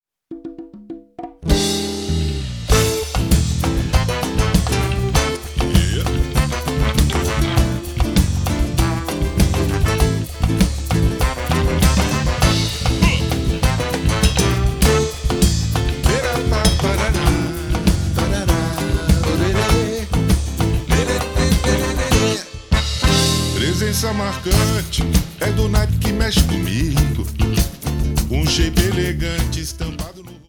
Bateria
Percusssão